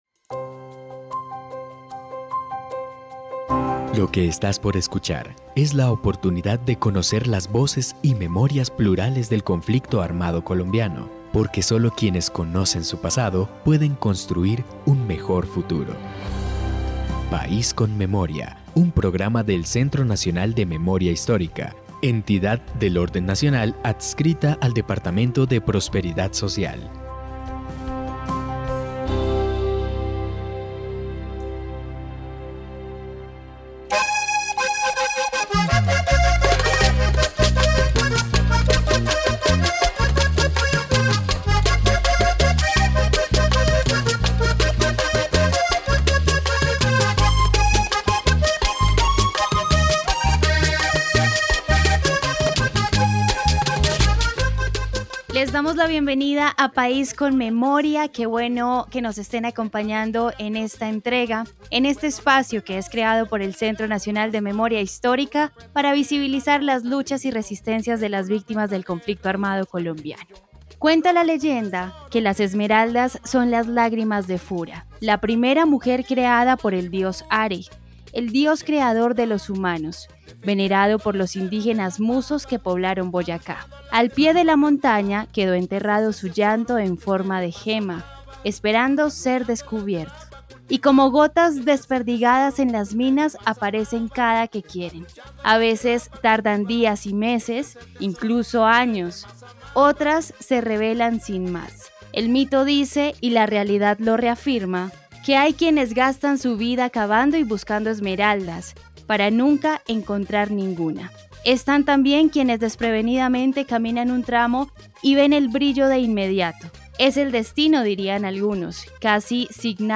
En País con Memoria hablamos con varias personas involucradas en el proceso de construcción de esta Iniciativa, quienes además, nos brindan un contexto de la región.
Descripción (dcterms:description) Capítulo número 16 de la cuarta temporada de la serie radial "País con Memoria".